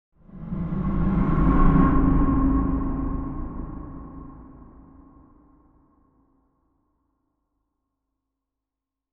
Blockbuster Trailer Cinematic Creepy Whoosh 1.ogg